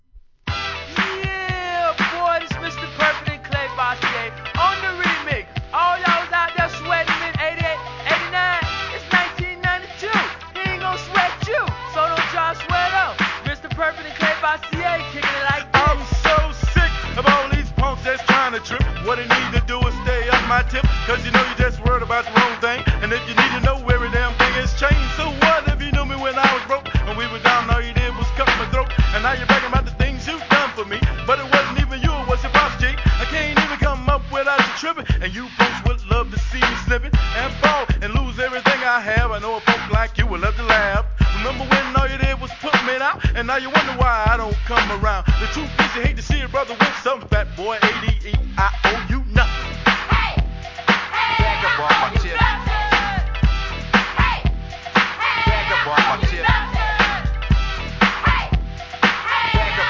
HIP HOP/R&B
マイナー・ニュースクールのPARTYチュ〜ン！